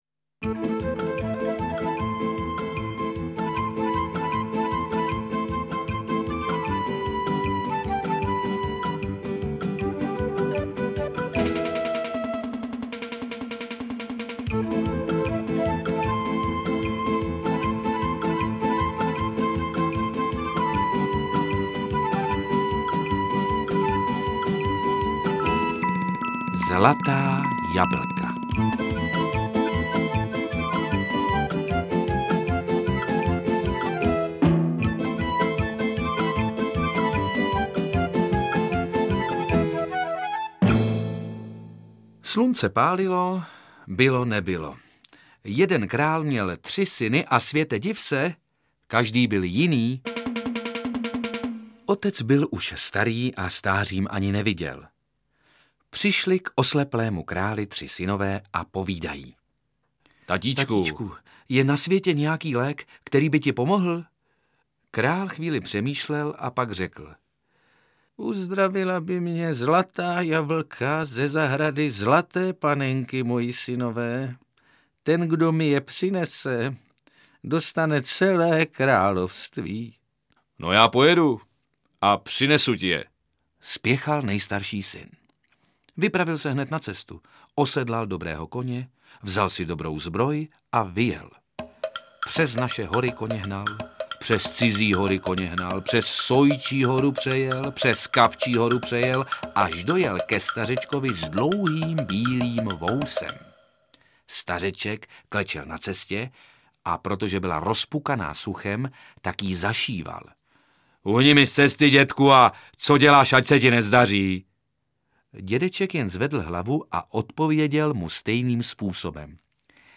Interpret:  Petr Rychlý
Jeden z nejpopulárnějších českých herců současnosti, Petr Rychlý, se stal v nahrávacím studiu vypravěčem pohádek z různých koutů světa, které mohou děti nejen pobavit, ale také seznámit s hrdiny a postavami pohádek z Anglie, Norska, Japonska, Irska, Islandu, Maďarska či Katalánska.
AudioKniha ke stažení, 9 x mp3, délka 1 hod. 17 min., velikost 69,9 MB, česky